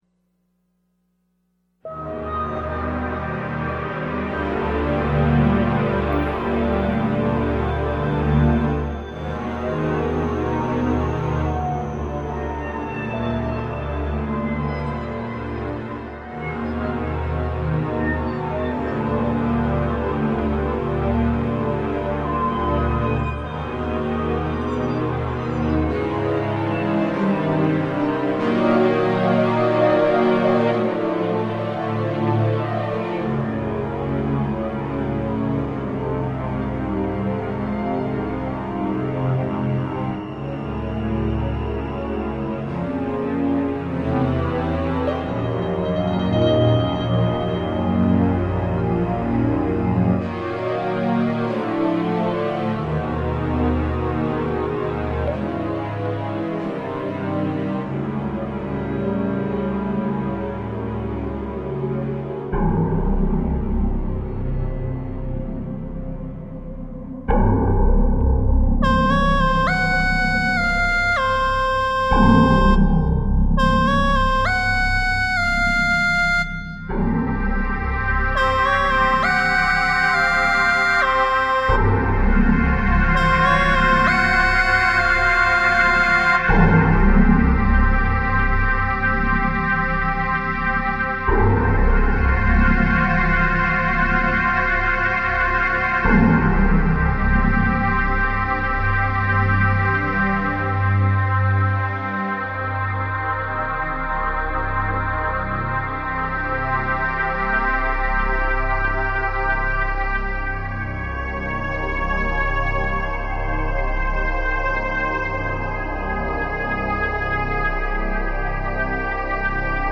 Dark ambient electronic